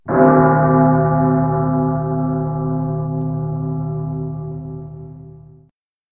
BellLarge.ogg